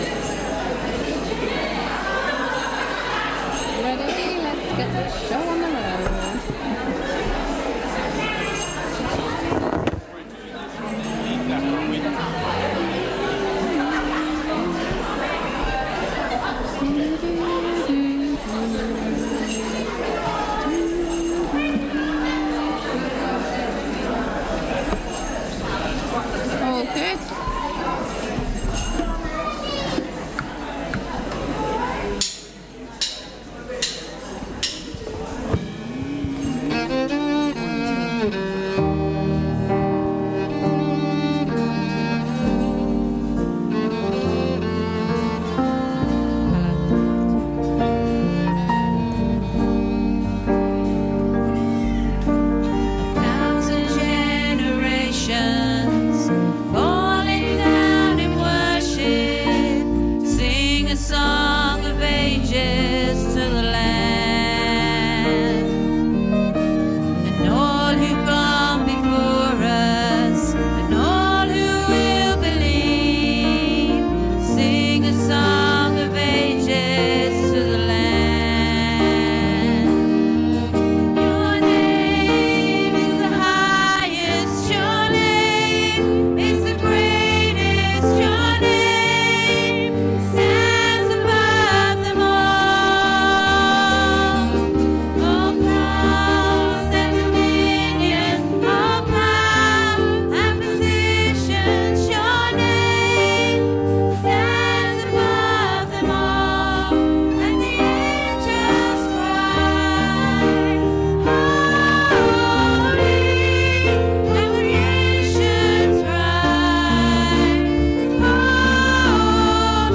Baptismal service - Sittingbourne Baptist Church
Service Audio